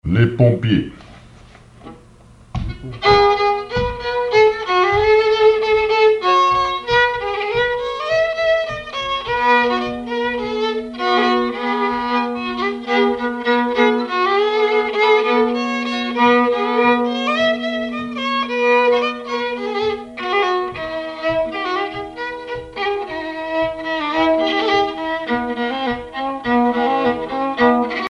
violoneux, violon,
danse : marche
Pièce musicale inédite